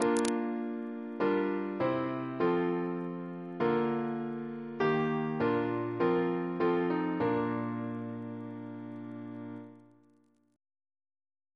Single chant in B♭ Composer: Thomas Kempton (1694-1762) Reference psalters: OCB: 146; PP/SNCB: 213